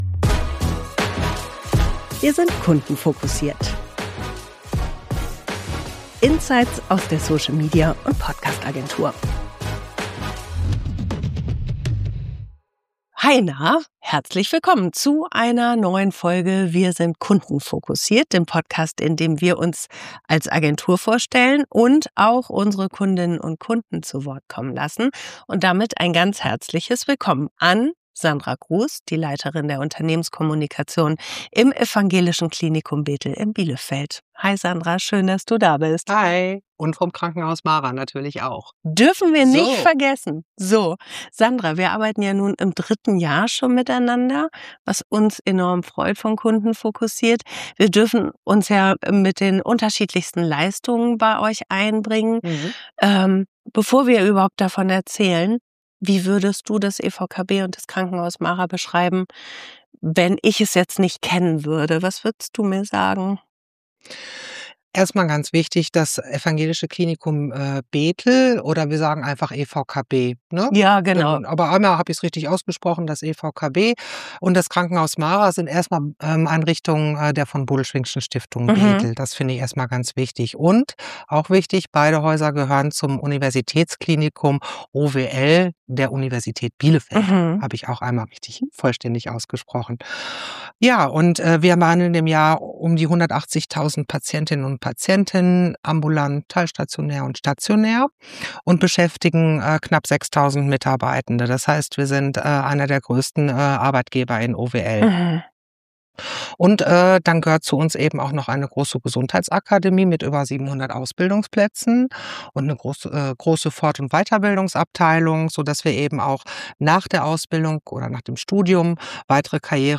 Kundentalk